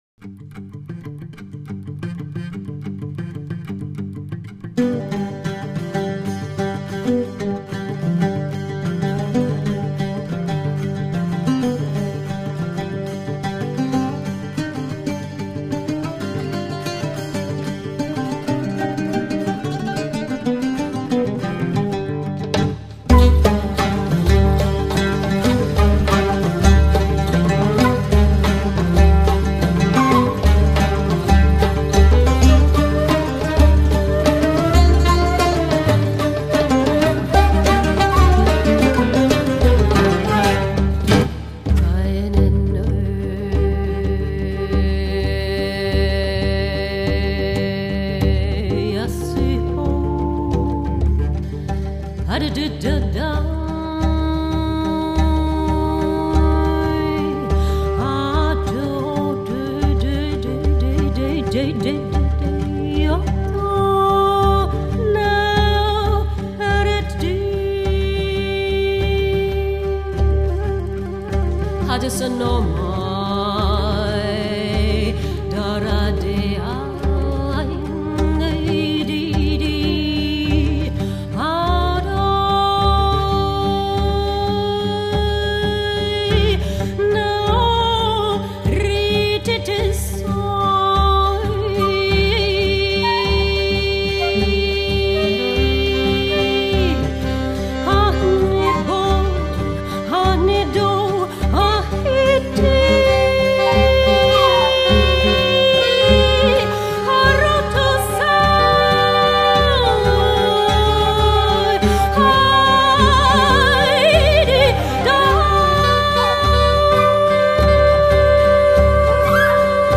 Hier einige Kostproben der music for a documentary film: